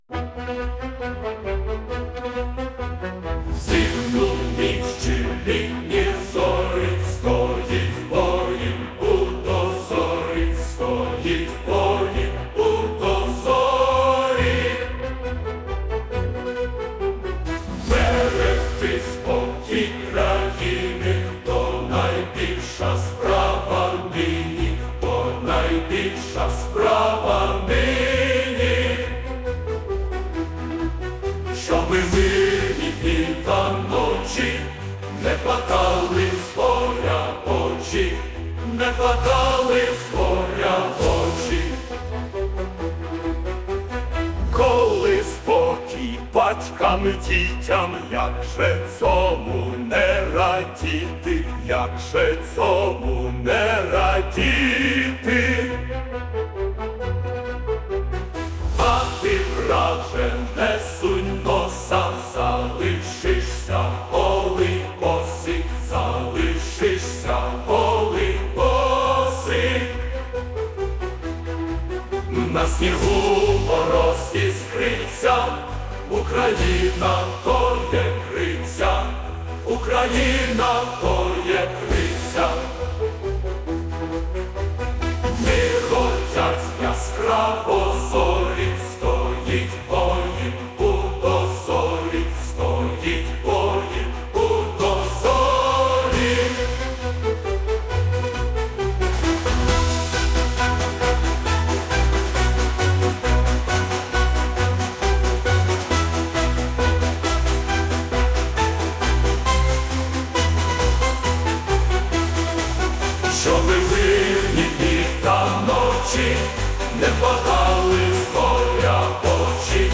Музична композиція ШІ
ТИП: Пісня
СТИЛЬОВІ ЖАНРИ: Ліричний